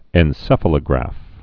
(ĕn-sĕfə-lə-grăf, -ə-lō-)